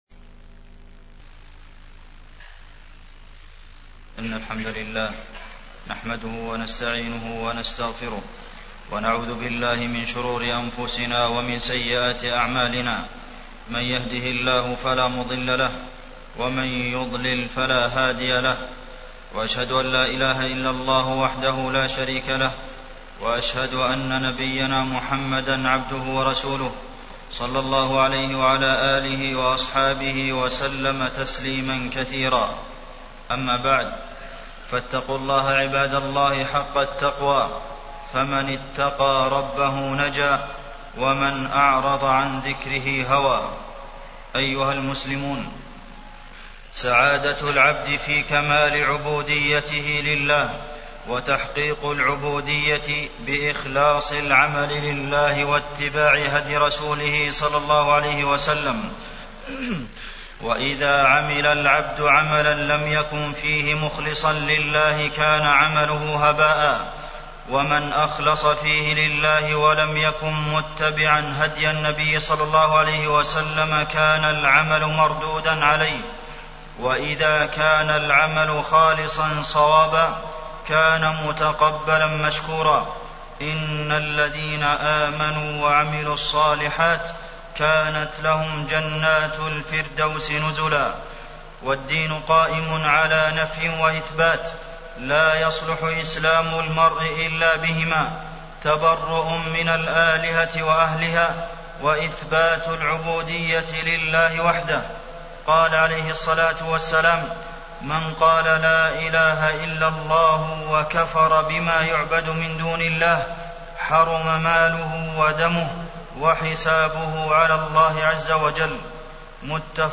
تاريخ النشر ٢٠ ذو القعدة ١٤٢٨ هـ المكان: المسجد النبوي الشيخ: فضيلة الشيخ د. عبدالمحسن بن محمد القاسم فضيلة الشيخ د. عبدالمحسن بن محمد القاسم توحيد الله